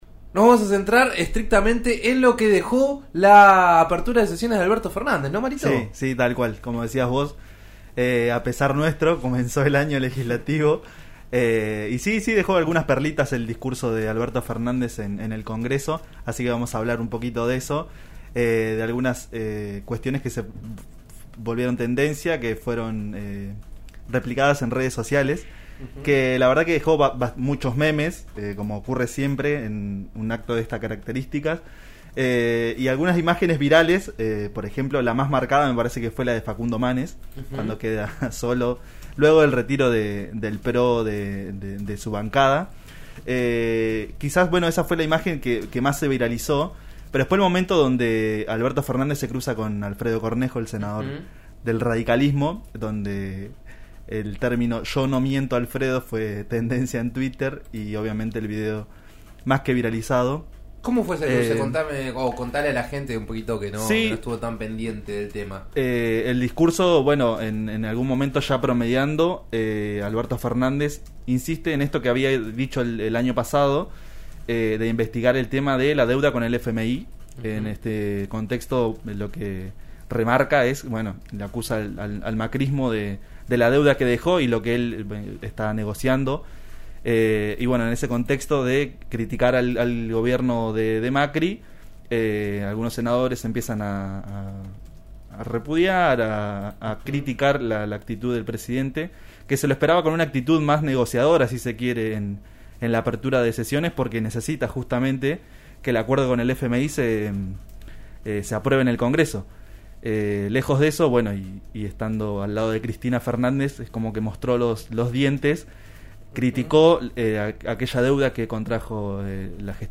Sesiones: la repercusión en redes del discurso de Fernández, en la columna de Virales de RN Radio